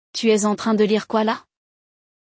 Nosso podcast 99% em francês foi criado para te ajudar a praticar o idioma de forma dinâmica, natural e envolvente. Neste episódio, acompanhamos um diálogo autêntico entre dois amigos, onde um deles lê um livro de um filósofo americano e se surpreende com uma citação.